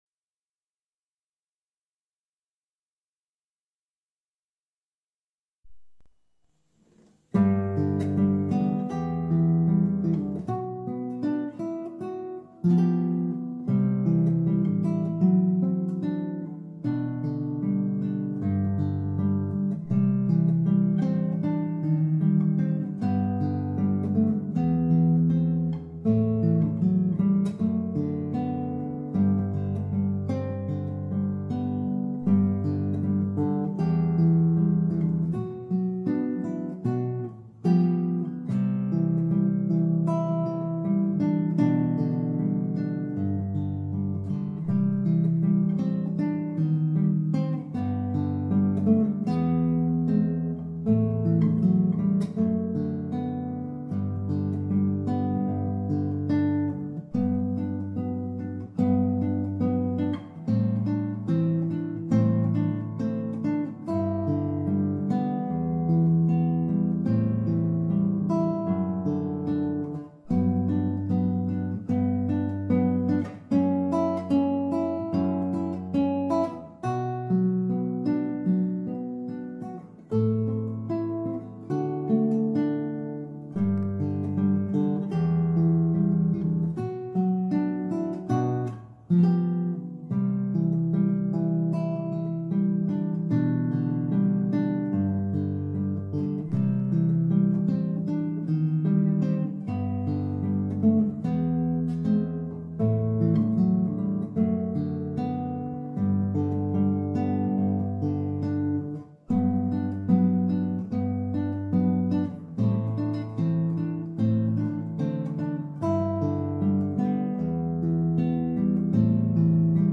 Audio Clip from the Tutorial
Capo 1st Fret - 4/4 Time